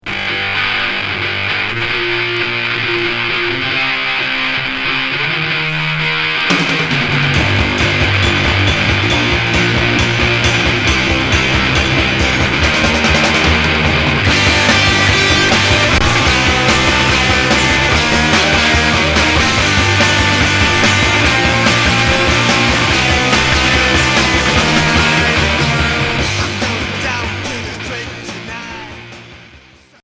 Garage Deuxième 45t retour à l'accueil